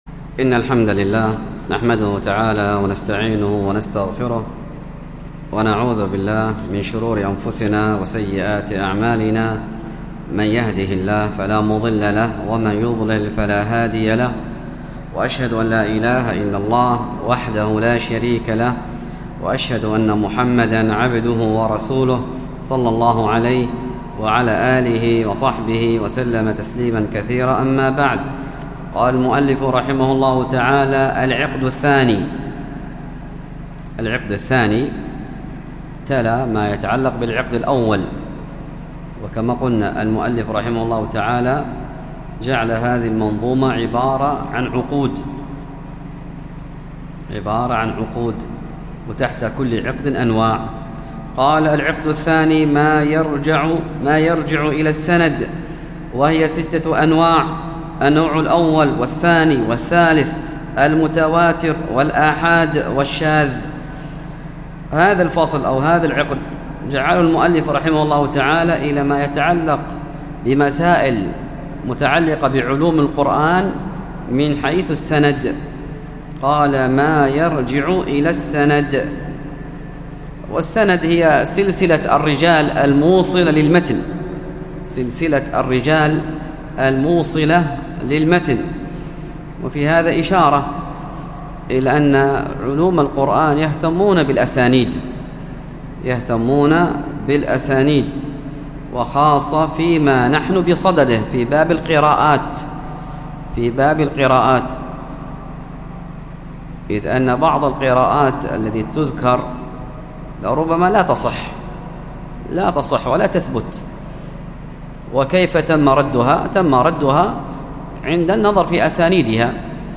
الدرس في التعليق على العقيدة الطحاوية 54، ألقاها